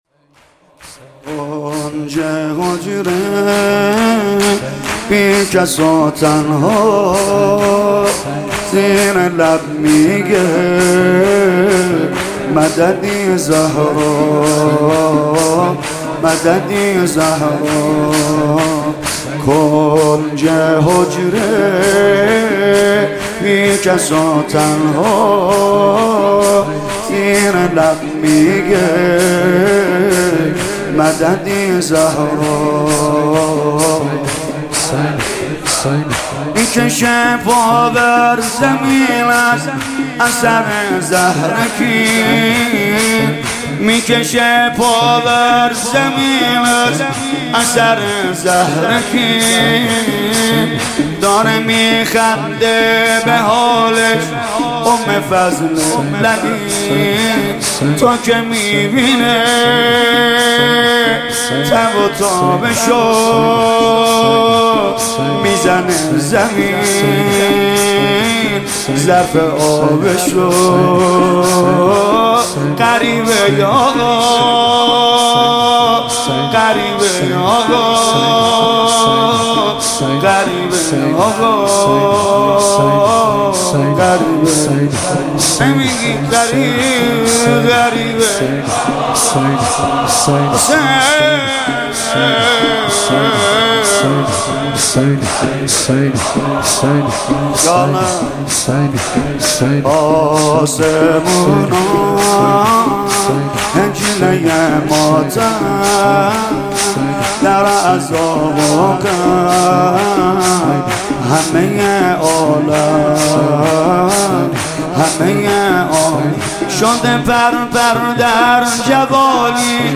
«شهادت امام جواد 1395» شور: کنج حجره بی کس و تنها